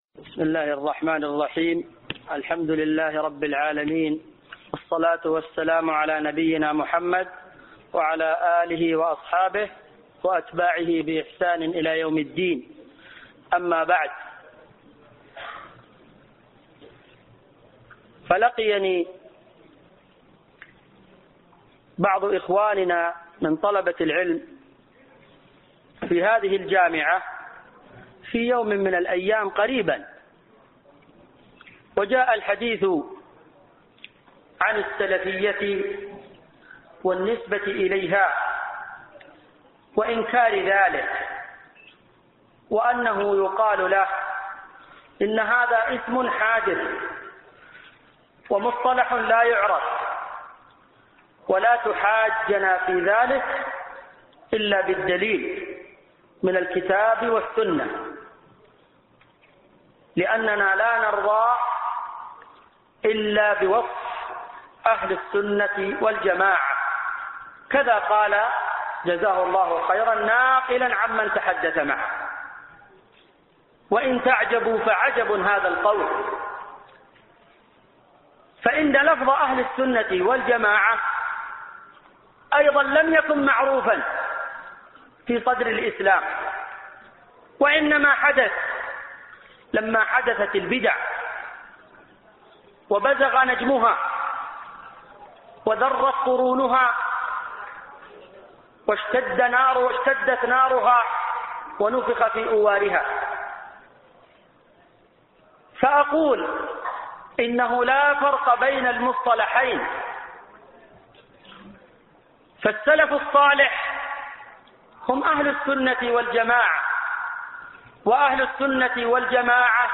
محاضرة
في مصلى كلية الحديث بالجامعة الإسلامية بالمدينة النبوية